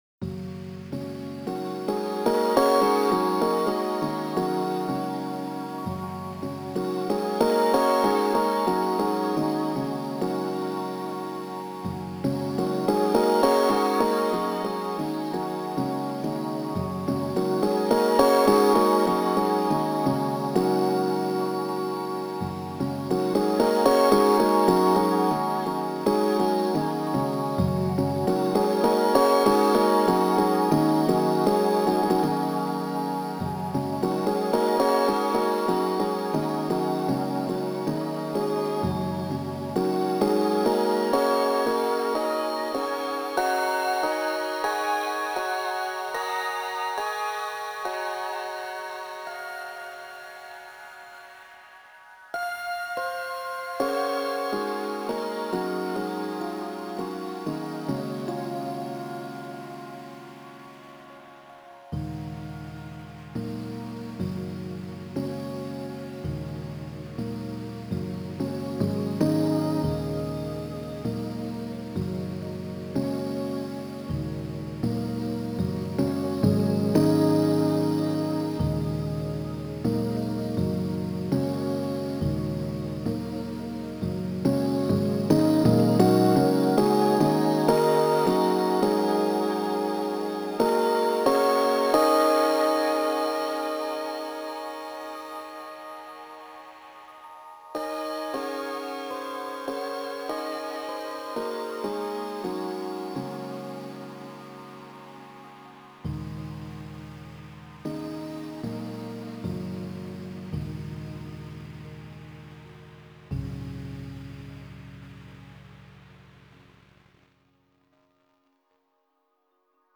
Ambient electronic sound